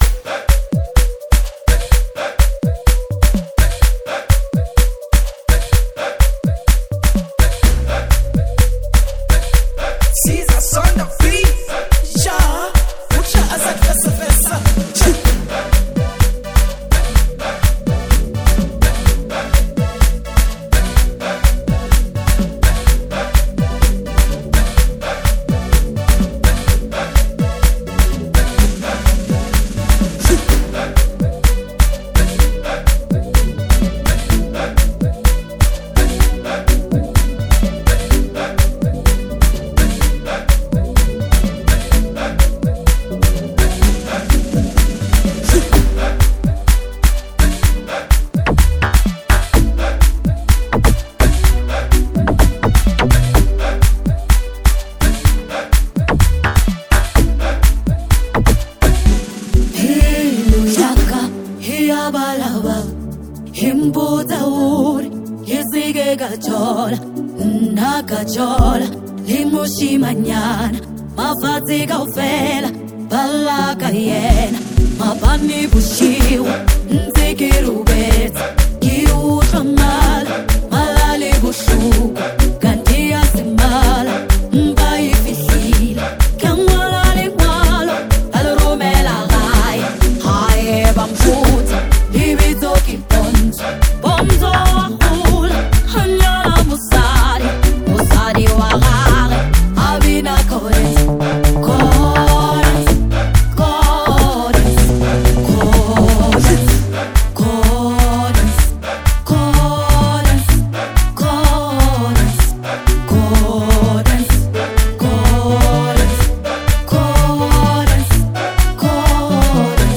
soulful vocals